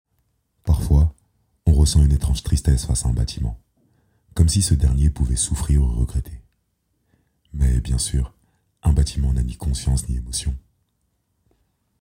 Test casting - Publicité voix grave
30 - 55 ans - Basse Baryton-basse